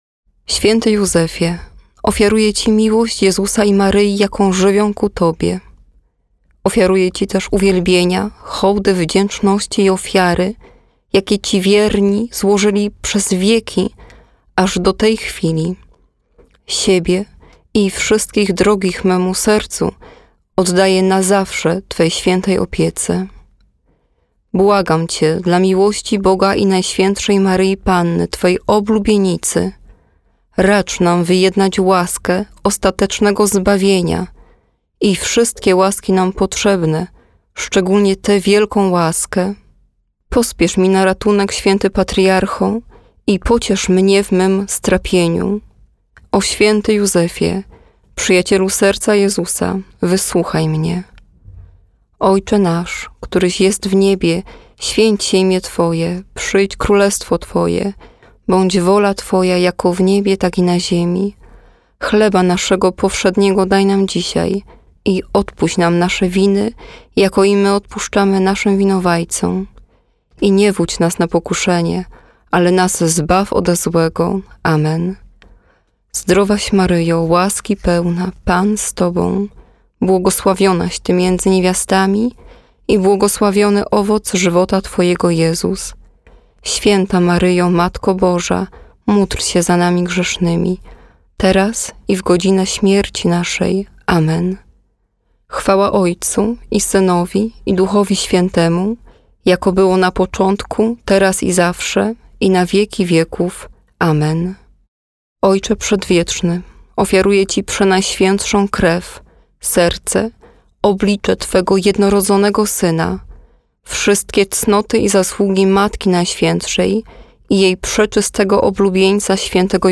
U progu wakacji weszliśmy do studia Radia eM, żeby nagrać wybrane przez Was w sondzie modlitwy.